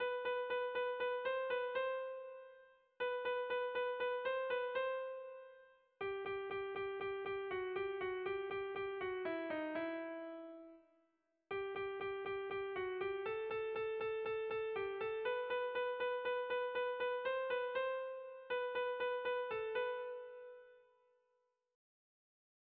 Tragikoa
Kopla handia
A-A-B-C